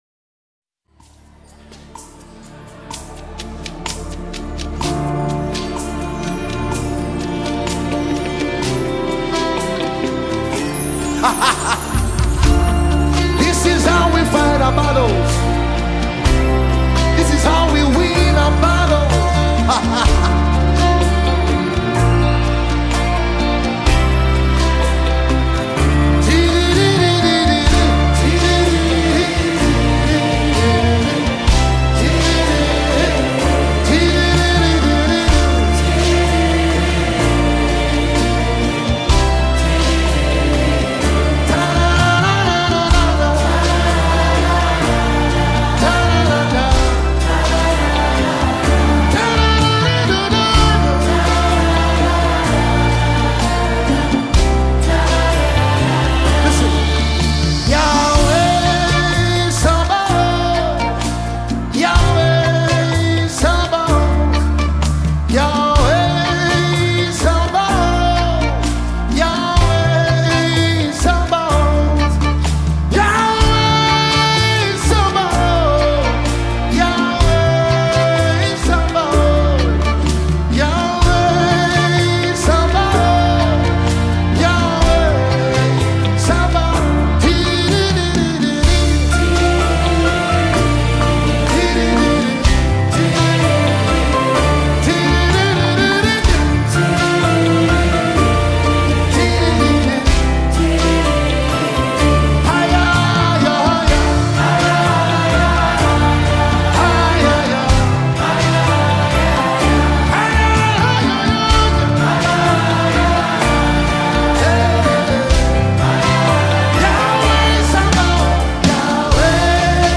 Genre: Gospel